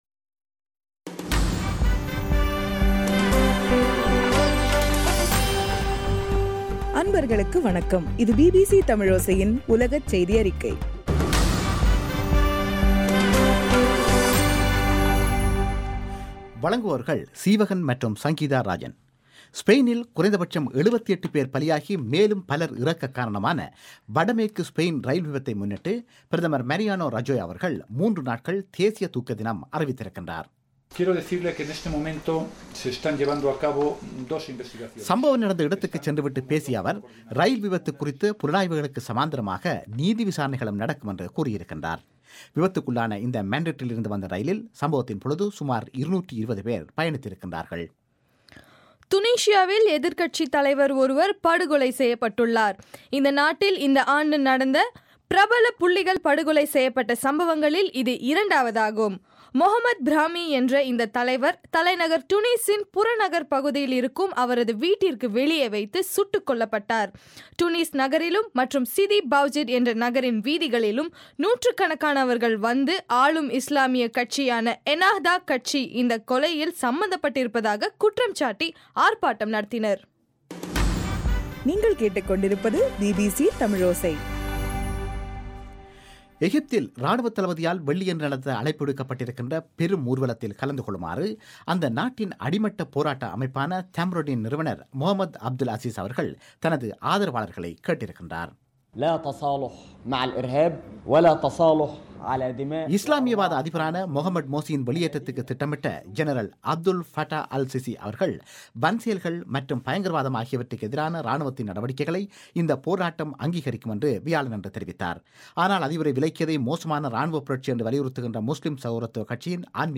ஜூலை 25 பிபிசி தமிழோசையின் உலகச் செய்திகள்